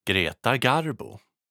ملف تاريخ الملف وصلات معلومات الصورة (ميتا) Sv-Greta_Garbo.ogg  (Ogg Vorbis ملف صوت، الطول 1٫5ث، 215كيلوبيت لكل ثانية) وصف قصير ⧼wm-license-information-description⧽ Sv-Greta Garbo.ogg English: Pronunciation of the name Greta Garbo in Swedish.
Standard Swedish / Uppländska /Stockholm dialect
Tools used in the making of this sound file: Reaper DAW, Neumann U87 microphone, Daking mic pre one preamp and Waves plugins for processing.
Sv-Greta_Garbo.ogg.mp3